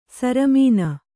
♪ saramīna